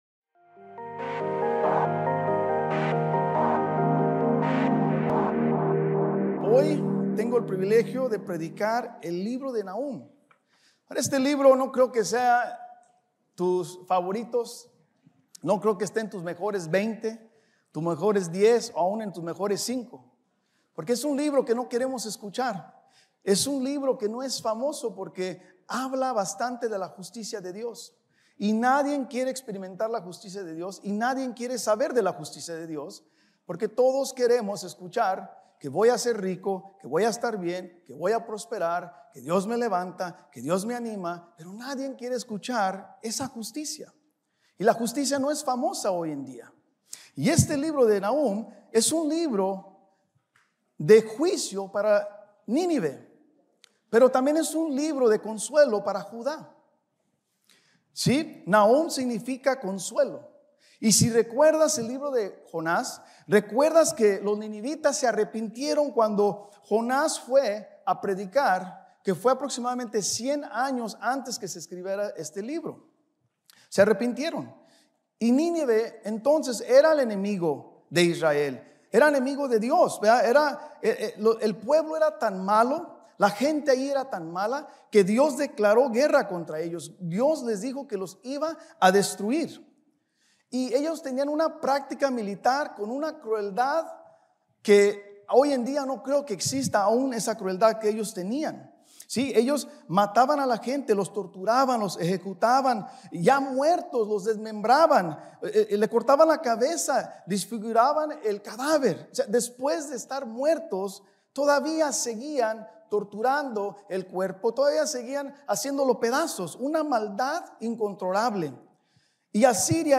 Estudio Biblico | Iglesia Vida Hammond
Predicando en Iglesia Vida Abundante Cicero.